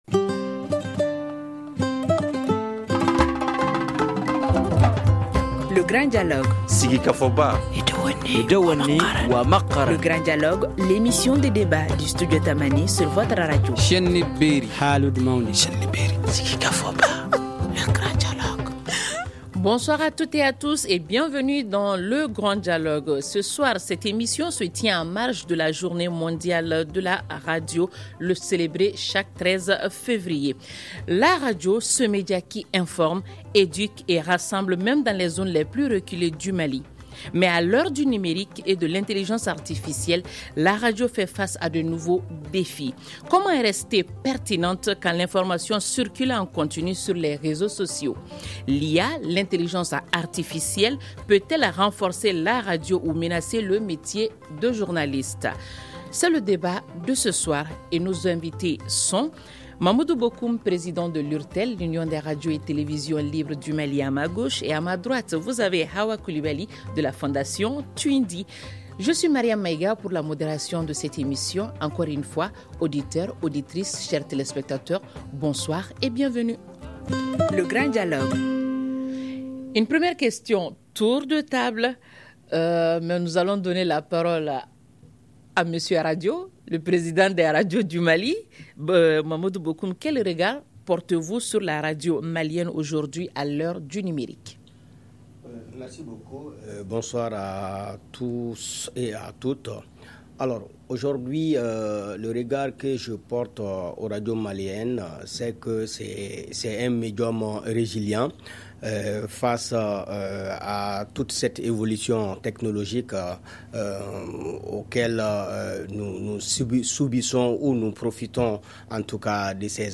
C’est le débat de ce soir.